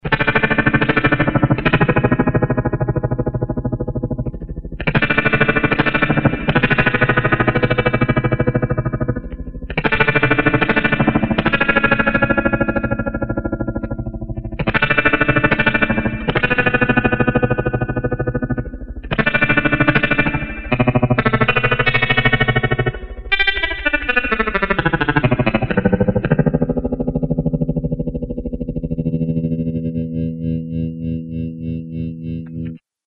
The Darth Fader is a tube tremolo pedal... with an all-tube signal path.
- Rate: Turn this knob for faster or slower modulation.
- Depth: The amount of fluctuation in the volume